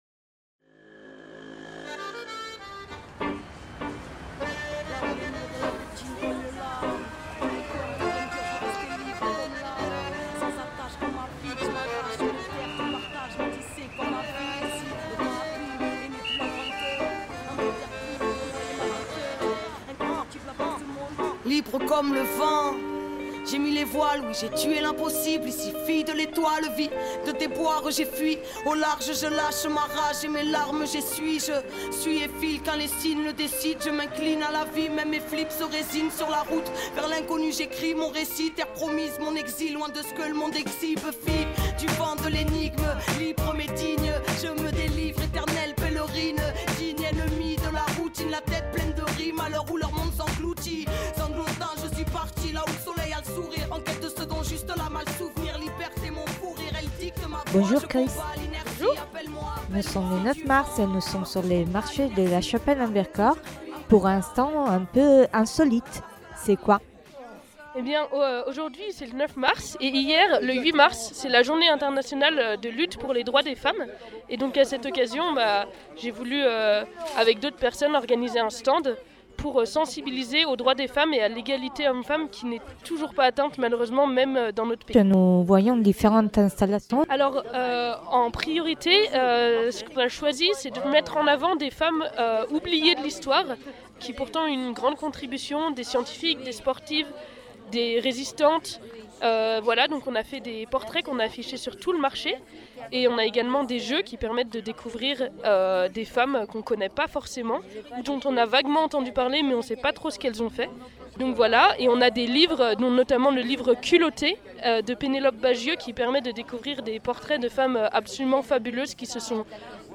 Le 9 mars 2023 un stand était présent au marché de la Chapelle en Vercors pour informer sur les droits de femmes et sur leur luttes. Des jeux, des posters, des chants, des livres, des documents et beaucoup d’échanges.